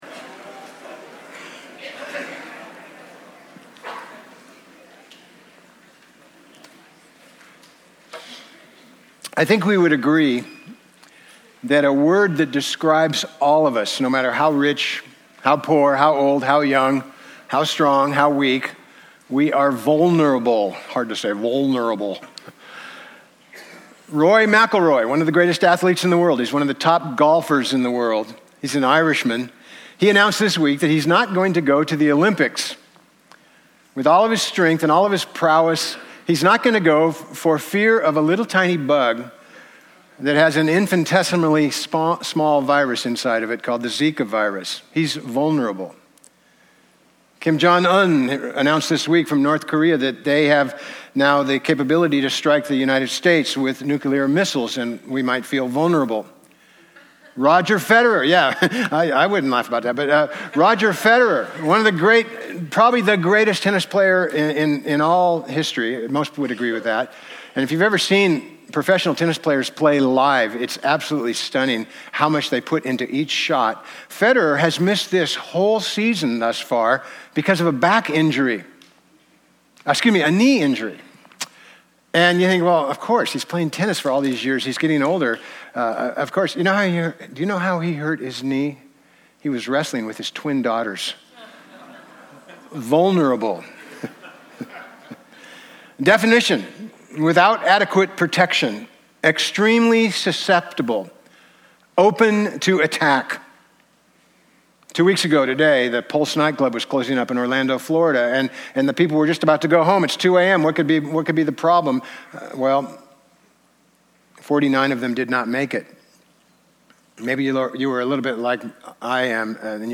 Bible Text: Romans 8:35-39 | Preacher